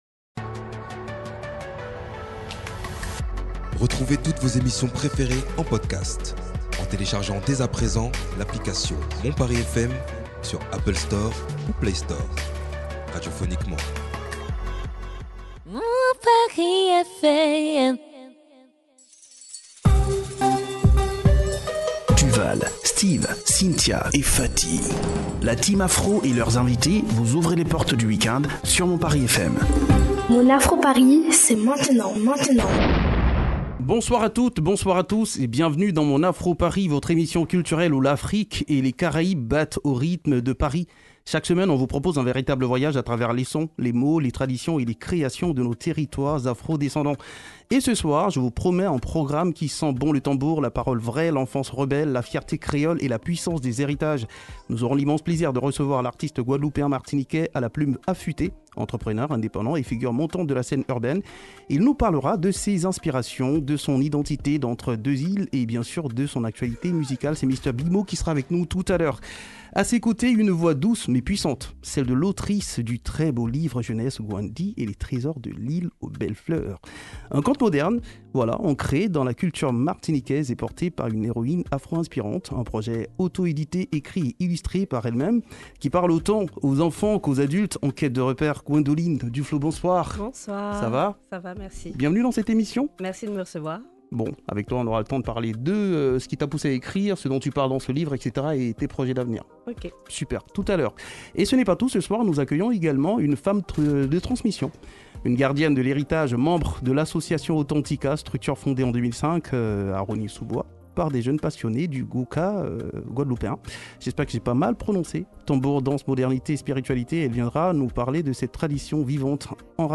Un chanteur enraciné entre flow et héritage, une chorégraphe porteuse des battements du gwoka, et une autrice qui fait éclore l’imaginaire afro- caribéen jeunesse.
La culture Afro s’écrit, se danse et se chante... en direct